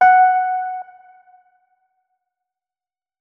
electric_piano